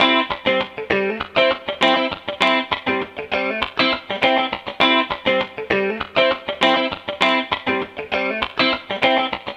Loops guitares rythmique- 100bpm 2
Guitare rythmique 26